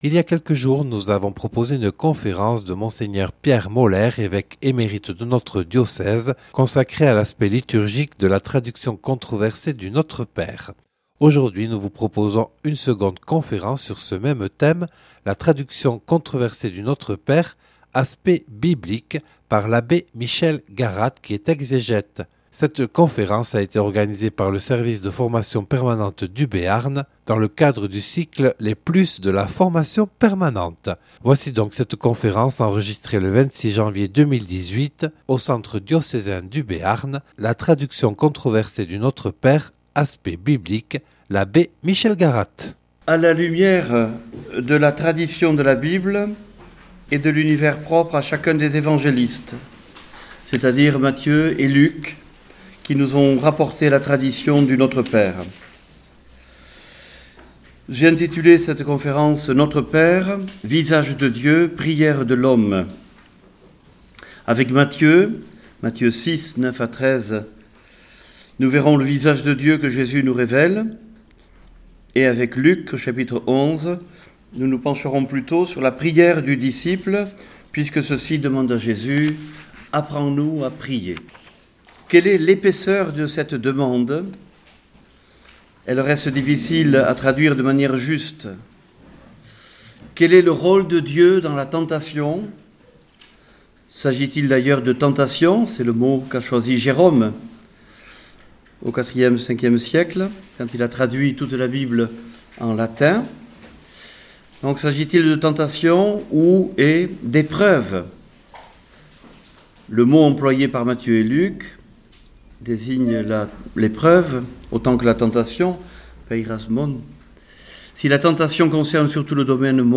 (Enregistré le 26/01/2018 à Pau lors d’une soirée proposée par le Service diocésain de la formation permanente en Béarn).